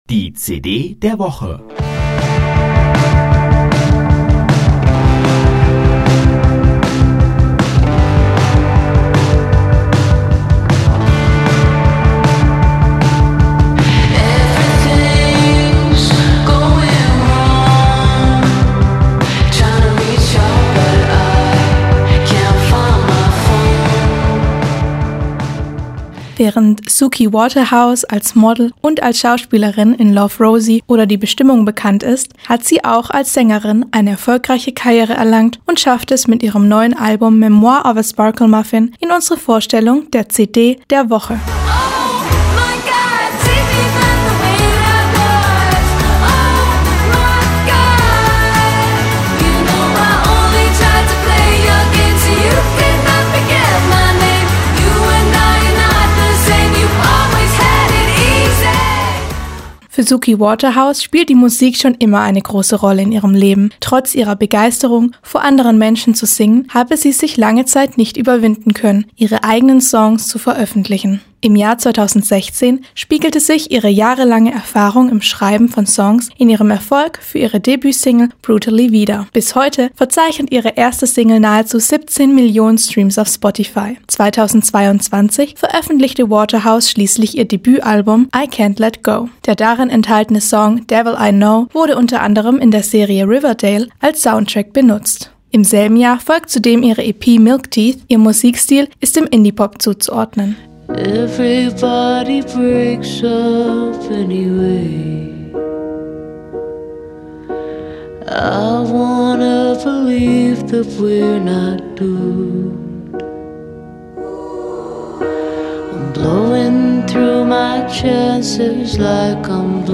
Ihr Musikstil ist dem Indie-Pop zuzuordnen.